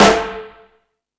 Metal Drums(03).wav